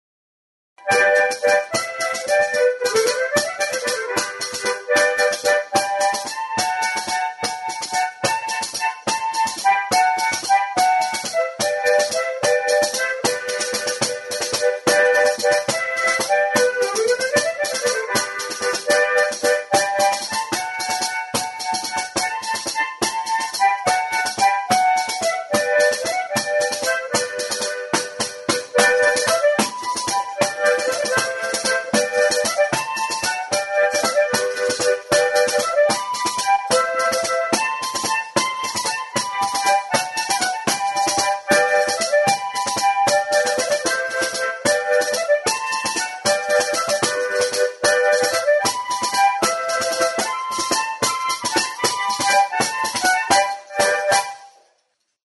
Music instrumentsPANDERETA; PANDEROA
Membranophones -> Beaten -> Tambourines
Recorded with this music instrument.